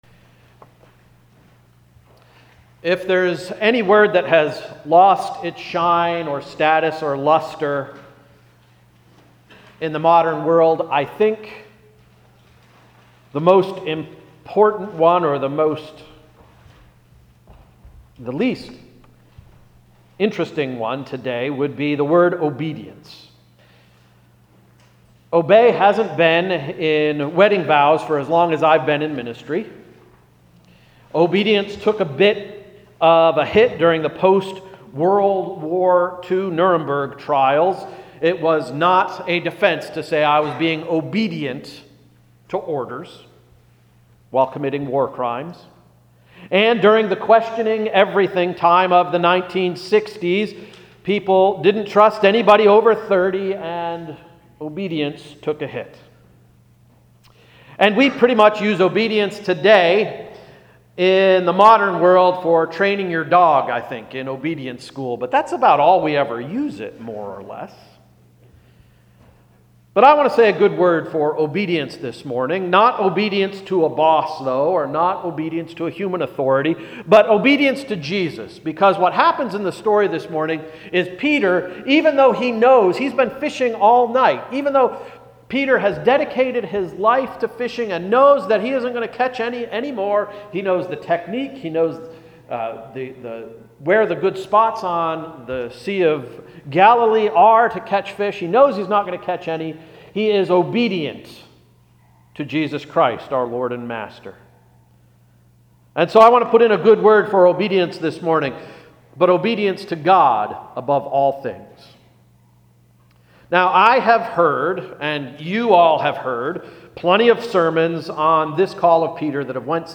January 29, 2017 Sermon — “You Need a Team”